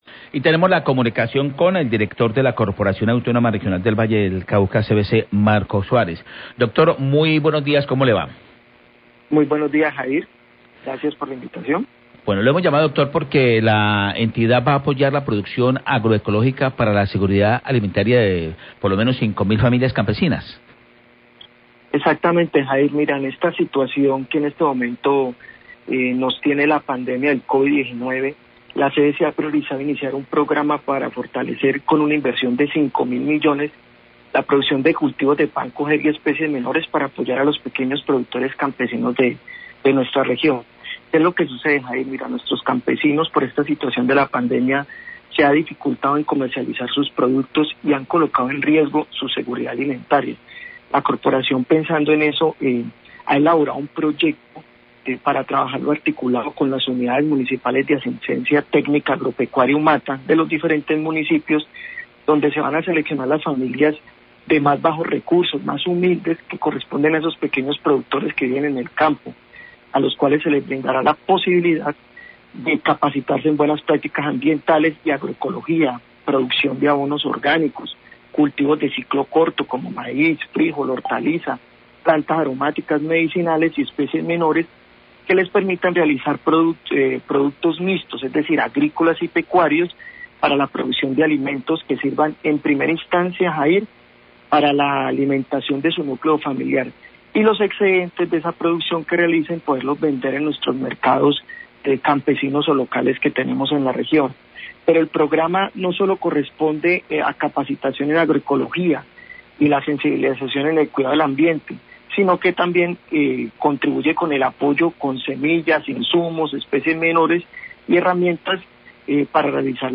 Radio
Entrevista al director de la CVC, Marco Suárez, sobre el apoyo de la producción agroecológica de los campesinos de la región, cuya seguridad alimentaria ha estado en riesgo por la emergencia del Covid-19. También se refiera a la aprobación del Plan de Acción 2020-2023.